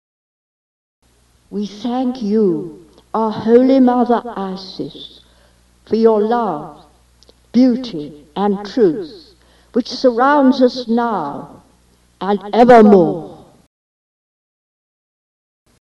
FOI Thanksgiving Prayer: